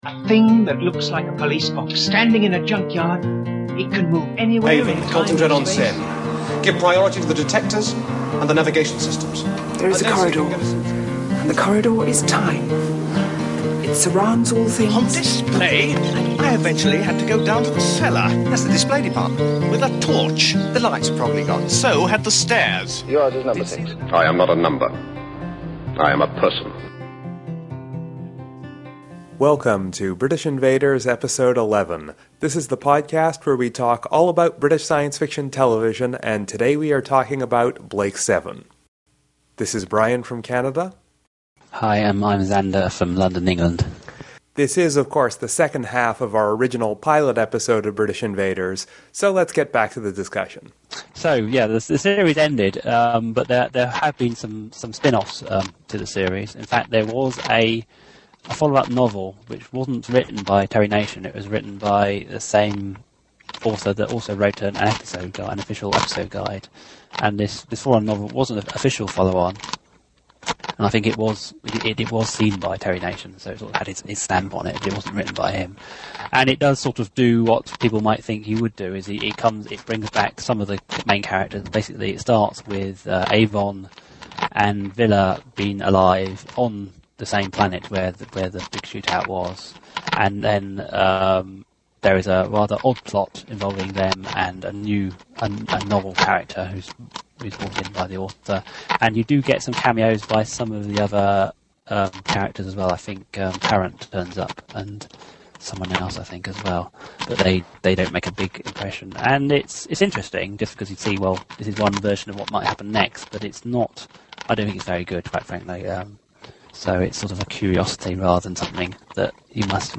This is the last half of our pilot episode, so it does not sound quite like our more recent episodes.